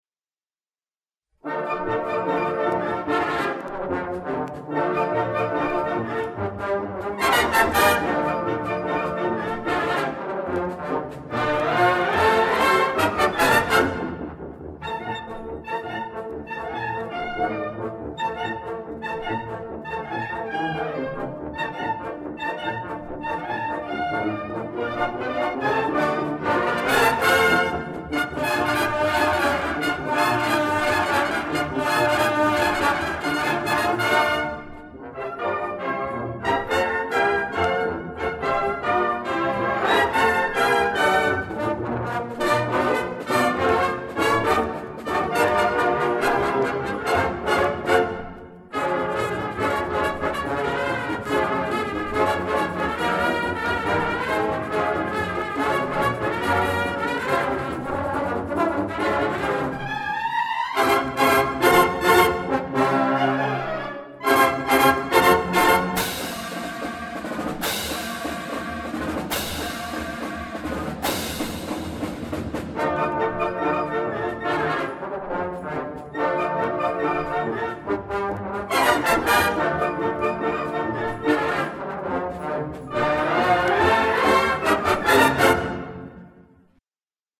recording session 1977